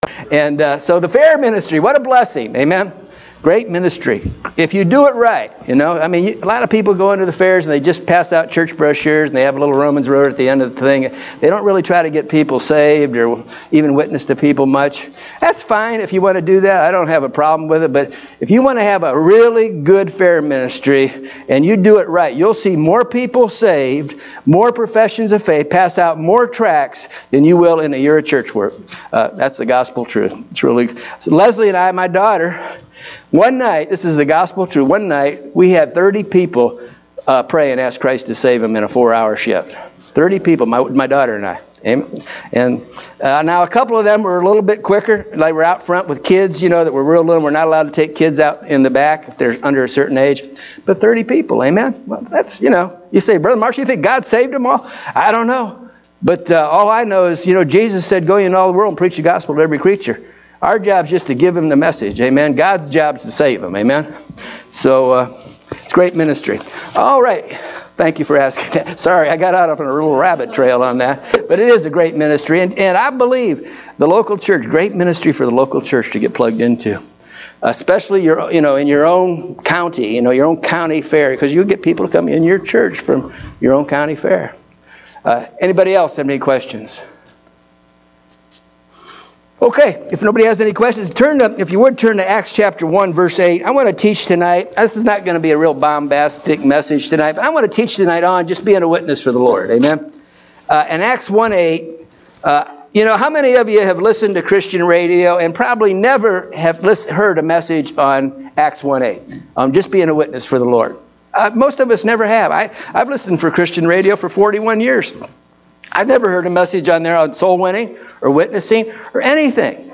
Evening Service (07/09/2017)